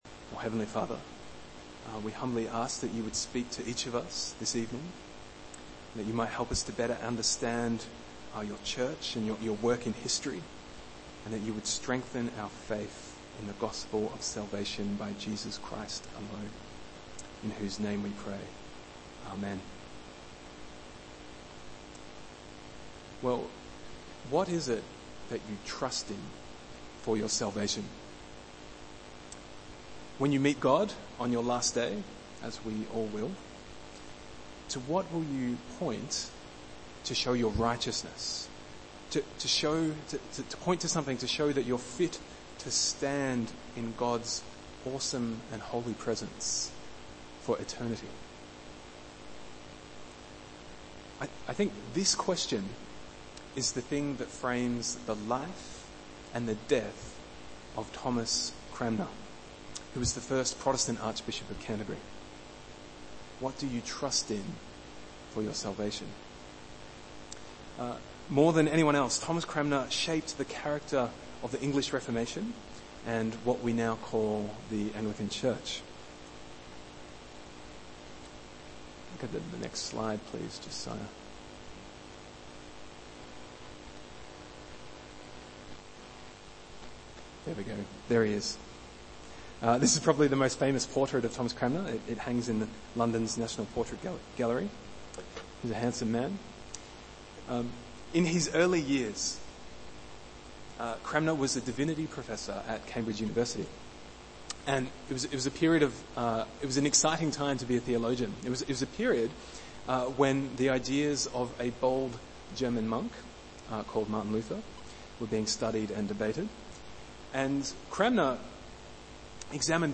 Bible Text: Romans 3:19-26 | Preacher